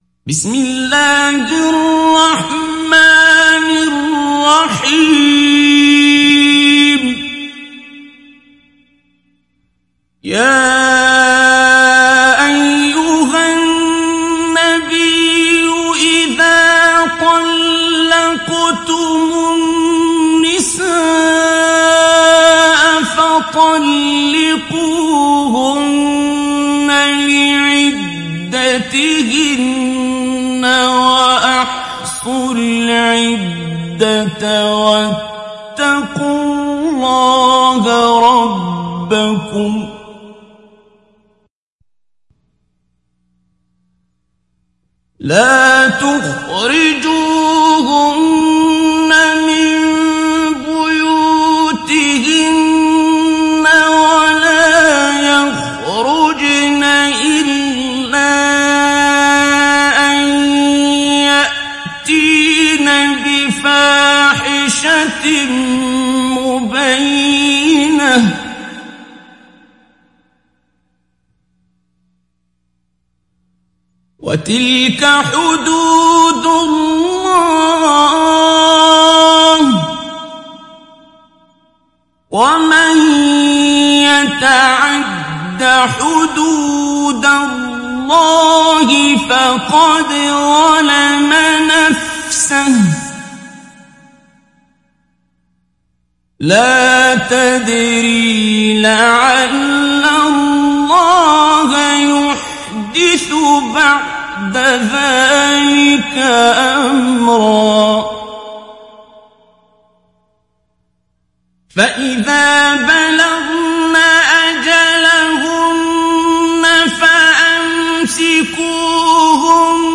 مجود